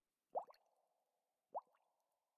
Minecraft Version Minecraft Version latest Latest Release | Latest Snapshot latest / assets / minecraft / sounds / ambient / underwater / additions / bubbles5.ogg Compare With Compare With Latest Release | Latest Snapshot
bubbles5.ogg